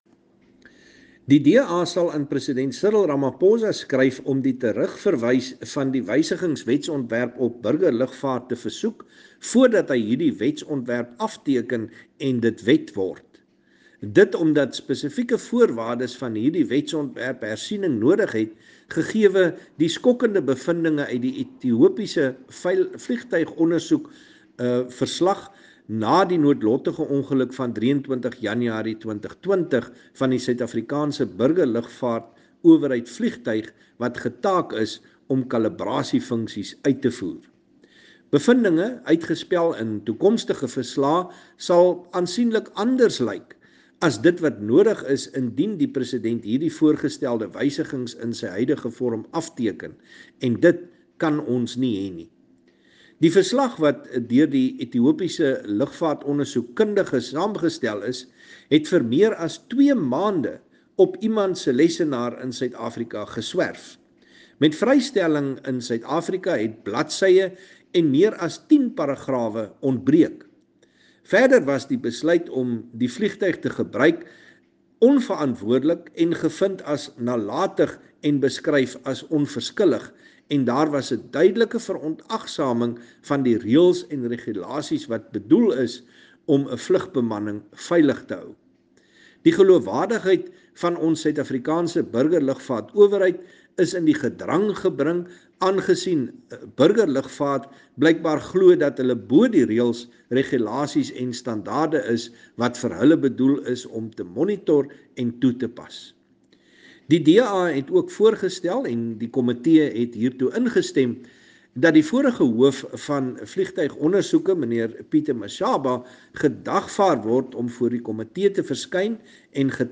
Afrikaans soundbites by Chris Hunsinger MP.
Chris-Hunsinger-Afrikaans.mp3